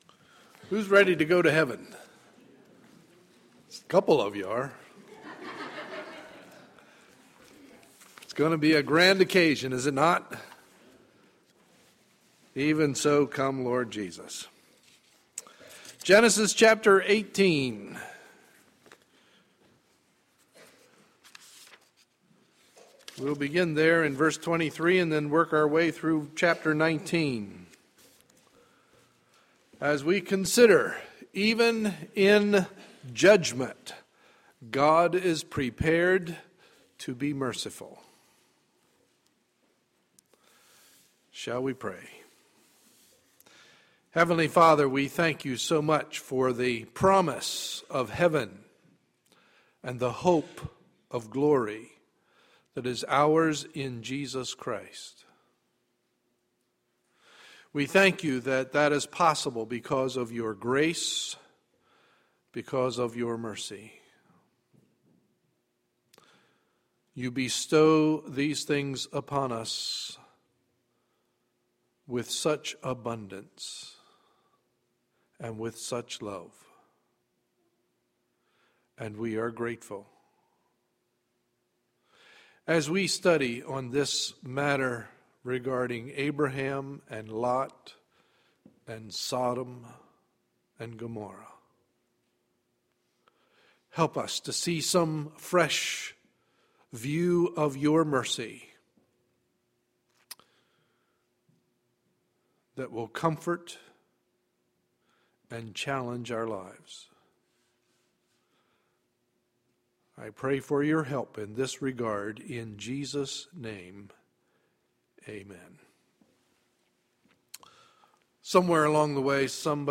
Sunday, February 3, 2013 – Morning Message